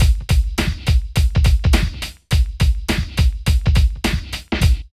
14 DRUM LP-R.wav